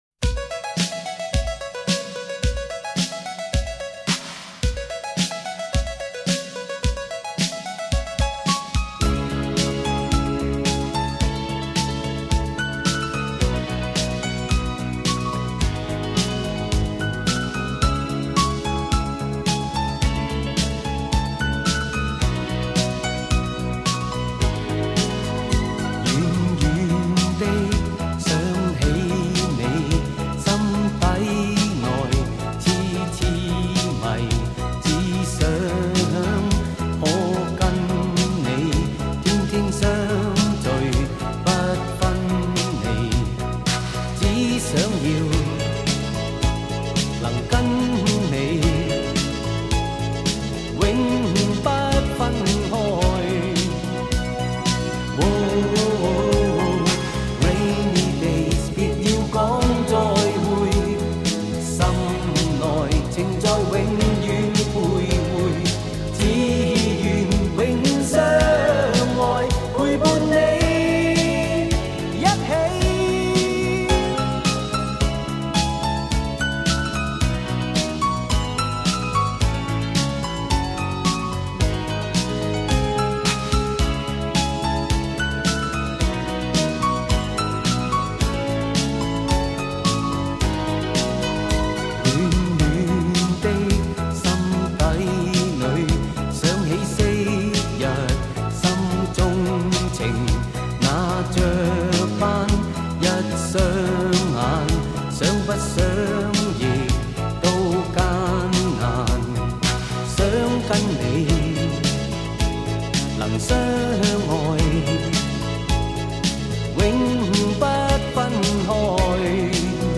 同时，又因为歌曲本身的清新、浪漫，很快被人所喜欢、传唱。
HQ Hi Quality CD